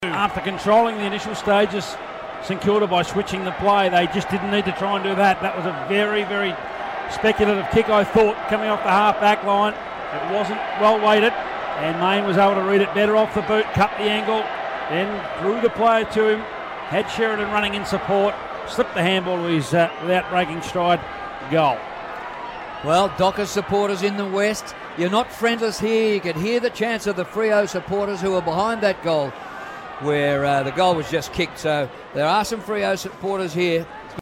Freo chant at Etihad